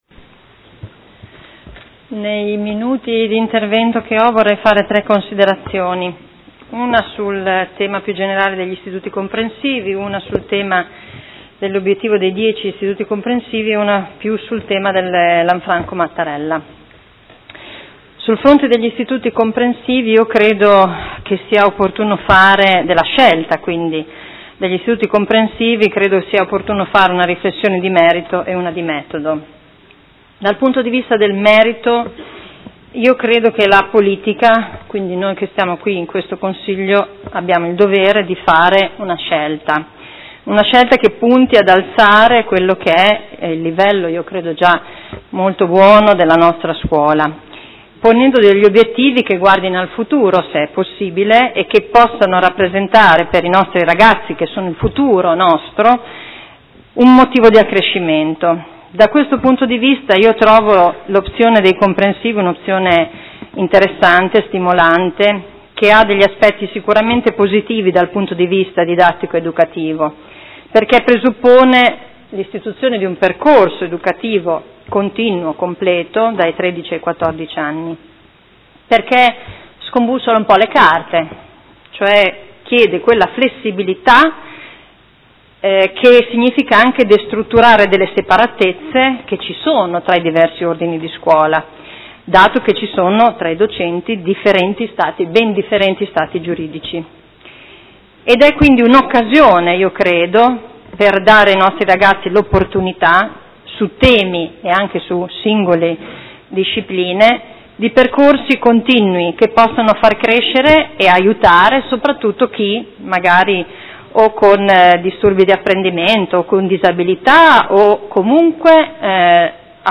Seduta del 26/11/2015 Dibattito. Delibera: Riorganizzazione della rete scolastica e costituzione degli Istituti Comprensivi